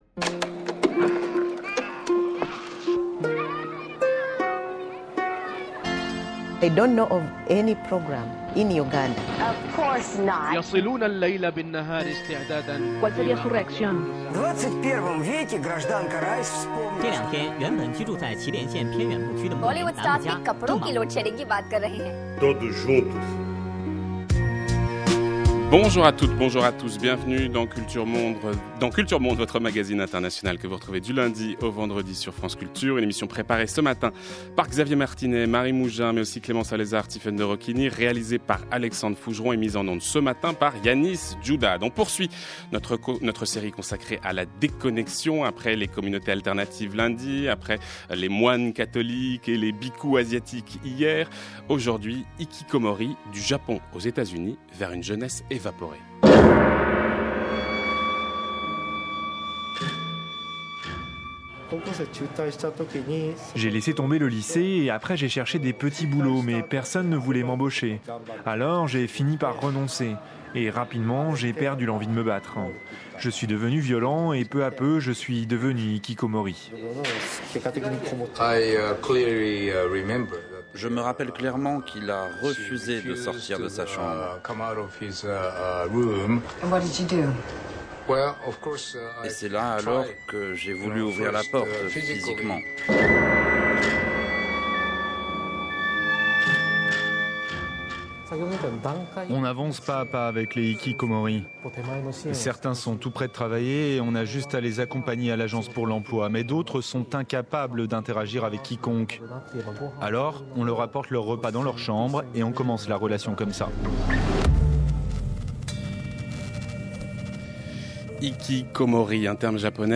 Emission de France Culture du 22 avril 2015